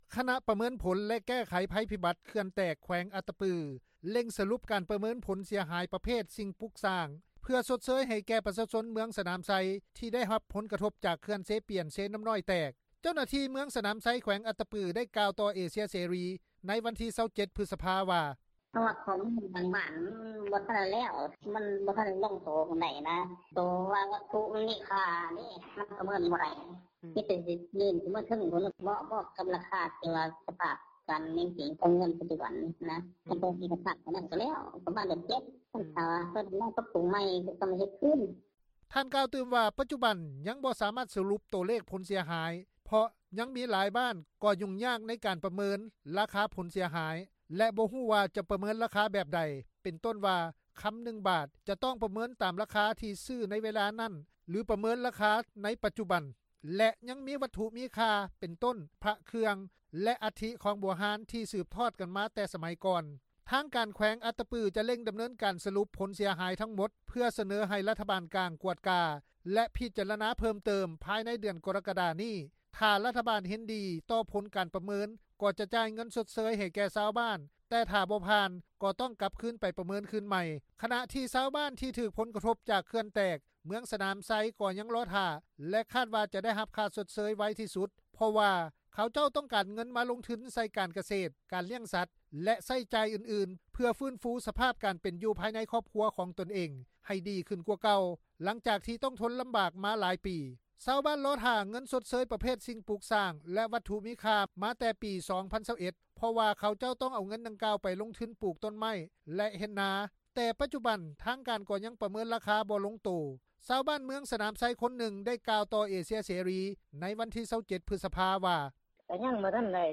ຊາວບ້ານເມືອງສນາມໄຊ ຄົນນຶ່ງ ໄດ້ກ່າວຕໍ່ວິທຍຸເອເຊັຽເສຣີ ໃນວັນທີ 27 ພຶສພາ ວ່າ:
ຊາວບ້ານເມືອງສນາມໄຊ ອີກຄົນນຶ່ງ ໄດ້ກ່າວຕໍ່ວິທຍຸເອເຊັຽເສຣີ ໃນວັນທີ 27 ພຶສພາ ວ່າ:
ເຈົ້າໜ້າທີ່ແຂວງອັດຕະປື ທ່ານນຶ່ງ ໄດ້ກ່າວຕໍ່ວິທຍຸເອເຊັຽເສຣີ ໃນວັນທີ 27 ພຶສພາ ວ່າ: